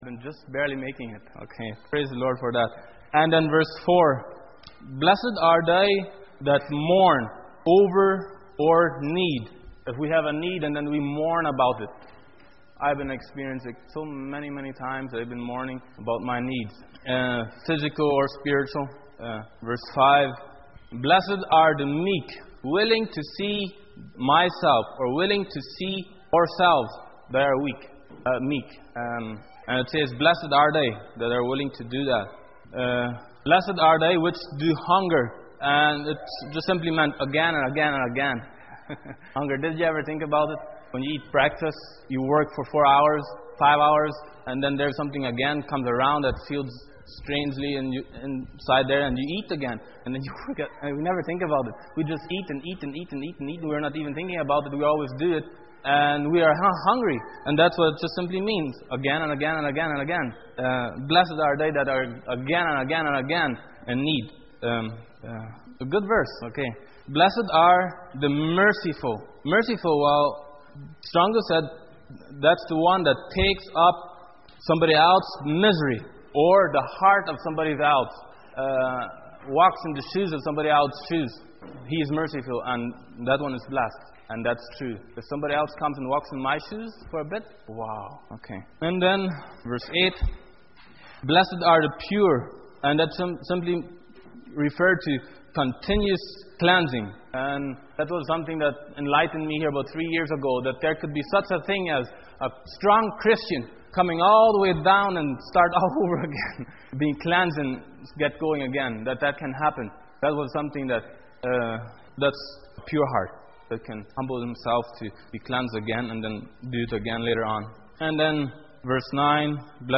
Series: Sunday Morning Sermon Passage: Matthew 5:1-11